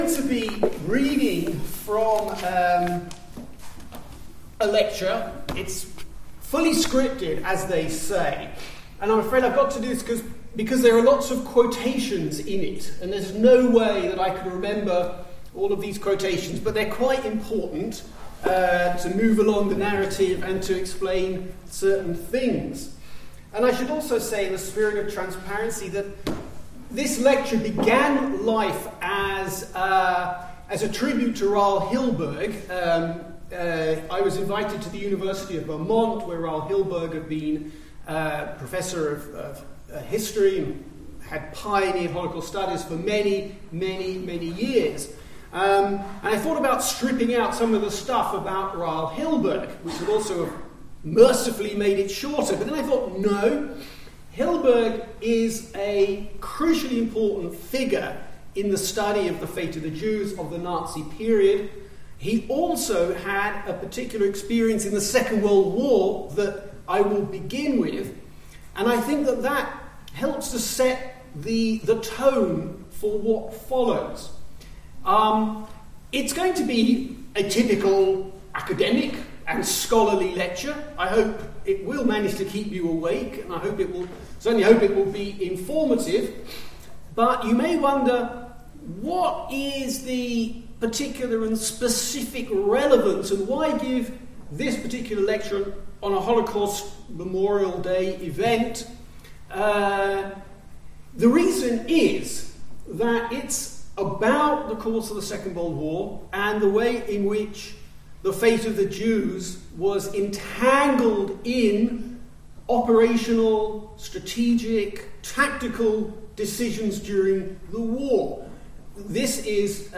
On Wednesday 21st January at 1pm in Holdenby Lecture Theatre 1 , Professor David Cesarani OBE (Royal Holloway, University of London) will give the University of Northampton’s annual Holocaust Memorial Day Lecture on ‘The Second World War and the Fate of the Jews.’